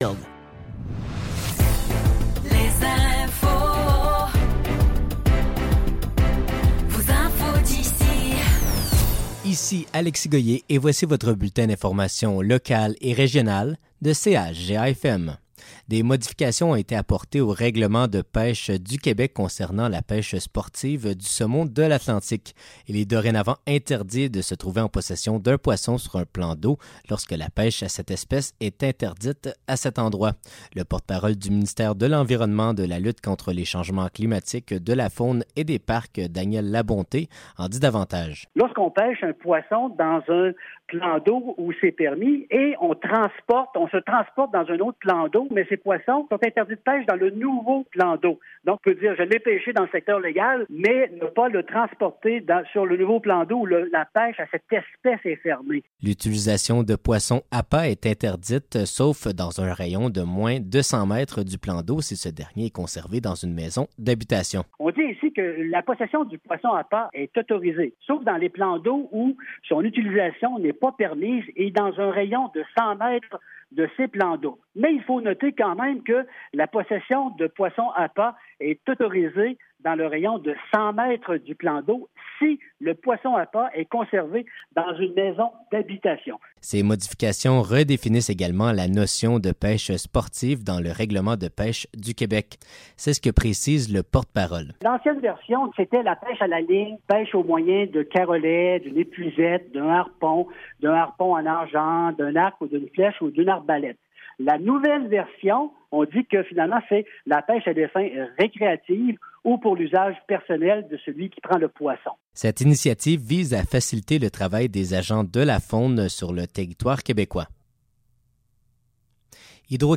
Nouvelles locales - 6 novembre 2024 - 12 h